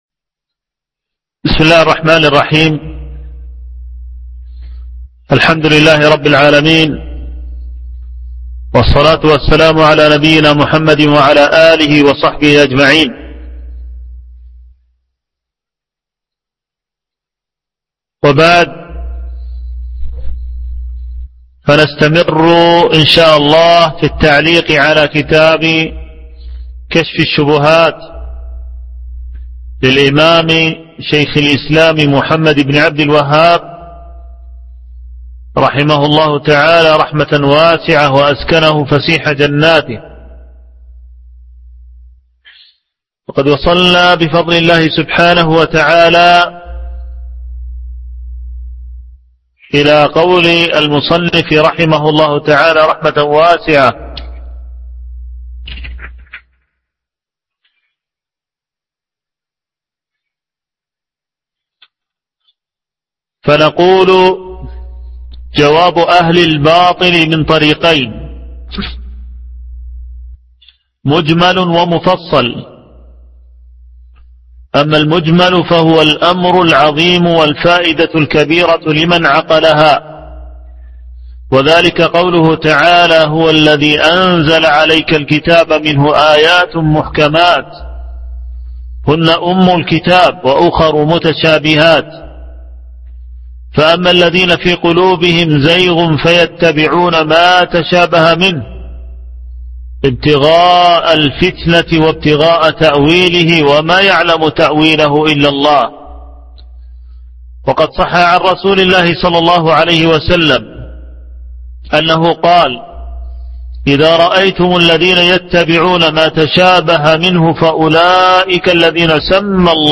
العنوان: الدرس السادس